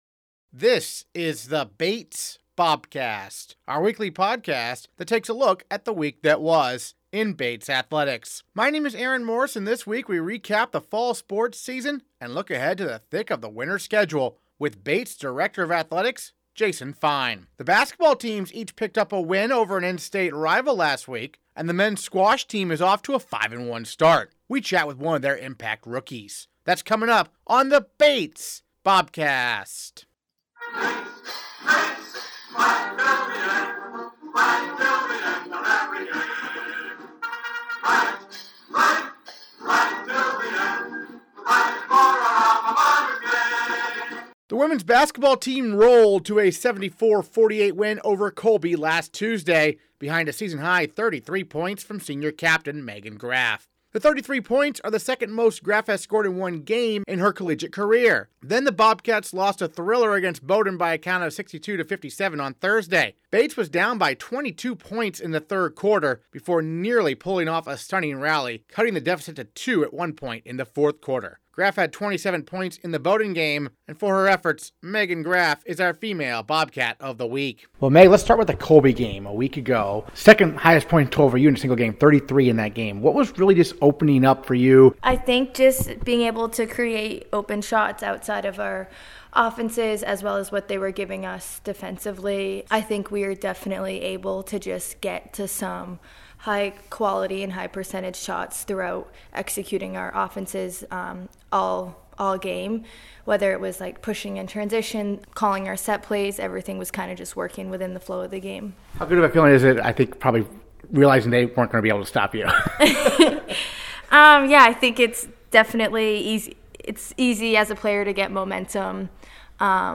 The basketball teams each picked up a win over an in-state rival last week, and the men's squash team is off to a 5-1 start. We chat with one of their impact rookies.
Interviews this episode: